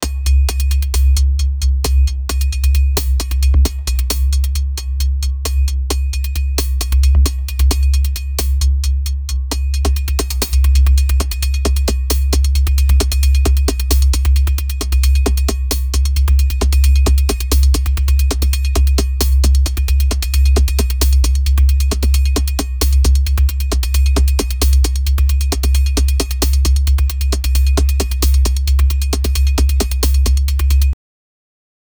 electro-octave-bass-dark--jtdybika.wav